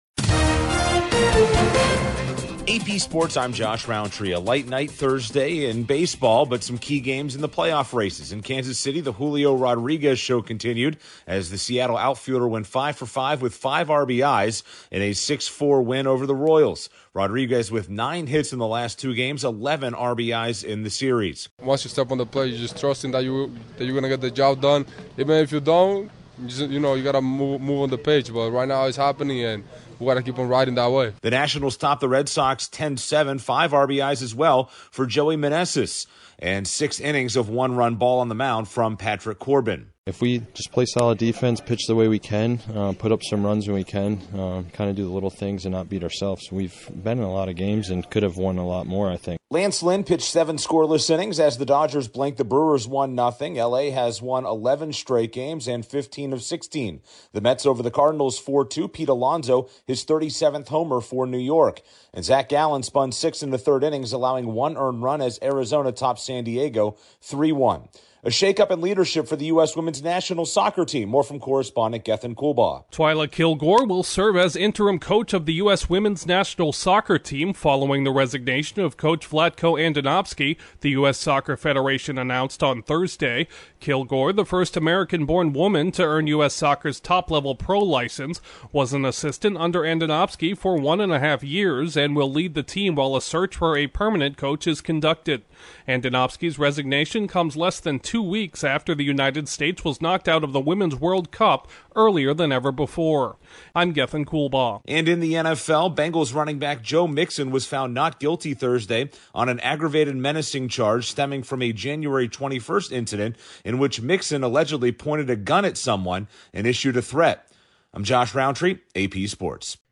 Another big game from Seattle’s top slugger, NL wins in the other MLB games Thursday, a change in US Women’s Soccer National Team leadership and a Bengals running back is acquitted. Correspondent